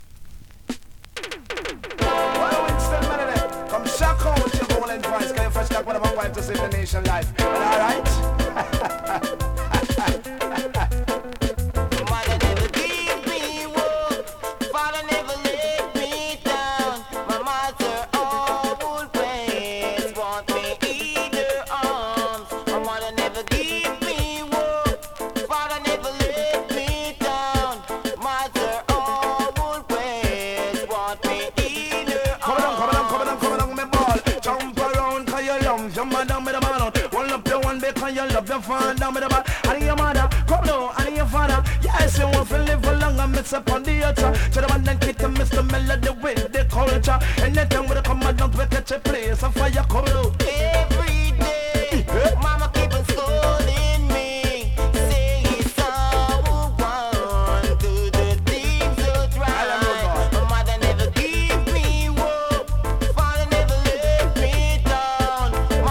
2026 NEW IN!! DANCEHALL!!
スリキズ、ノイズ比較的少なめで